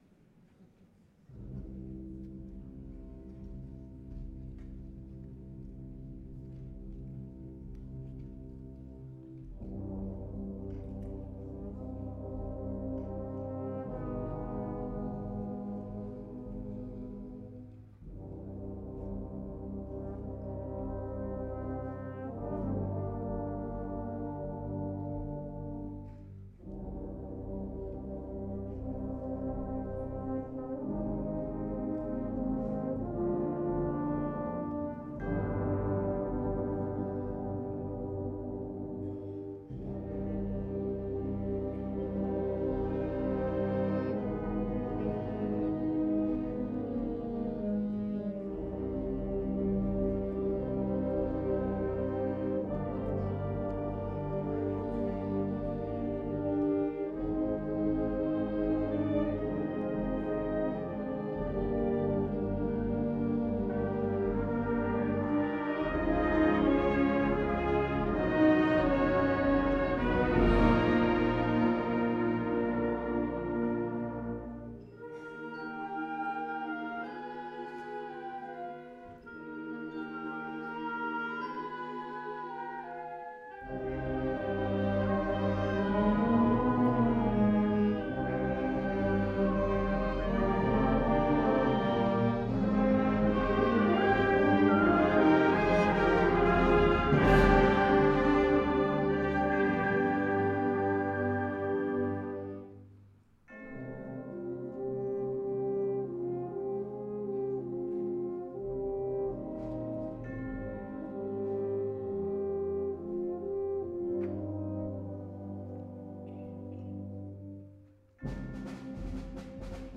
2014 Summer Concert